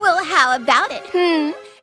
Worms speechbanks
REVENGE.WAV